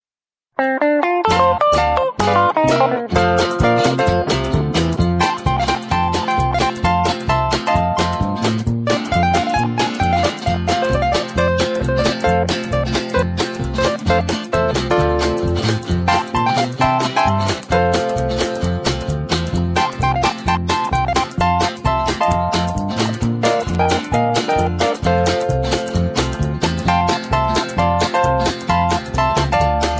Inst